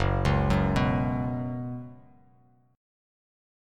F#sus4 chord